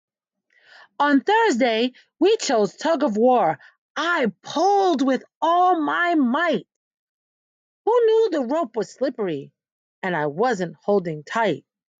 Story telling from enthusiastic teachers.